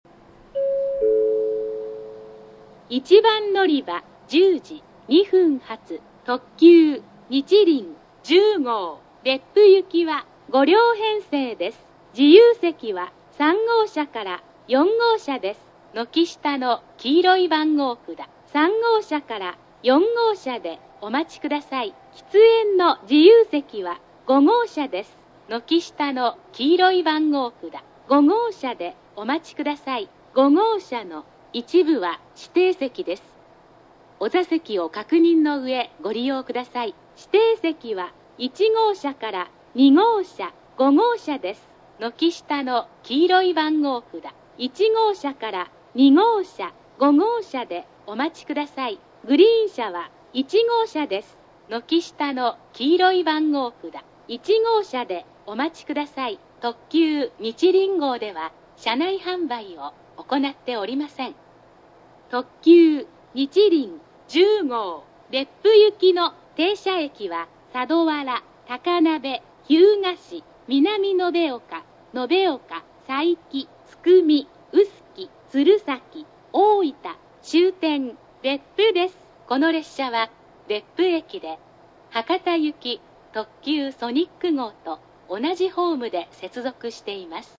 駅の音
スピーカー：ソノコラム
音質：B
案内放送（特急にちりん10号）　(427KB/87秒)
また、近年流行のテンションの高い放送ではなく「宮崎↓、宮崎↓」とイントネーションが下がっています。
音割れ等無く、九州内では綺麗な部類の音質です。